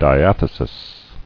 [di·ath·e·sis]